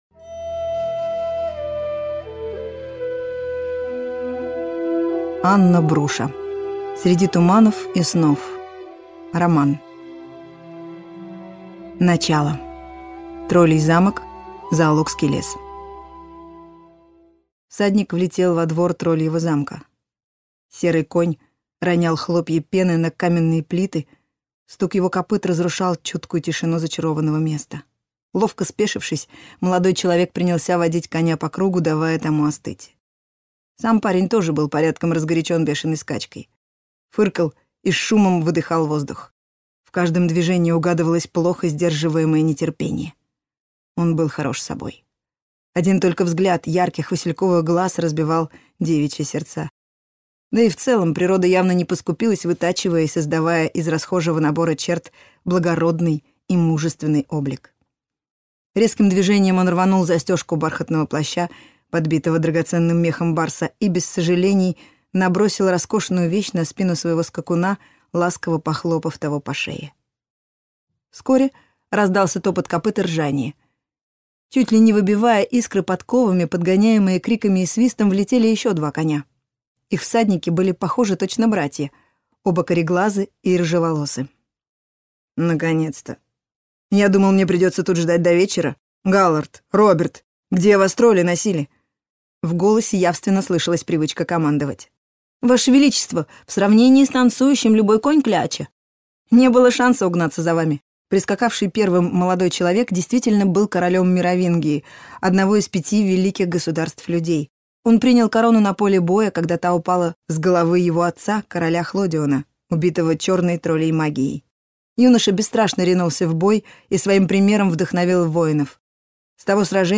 Аудиокнига Среди туманов и снов | Библиотека аудиокниг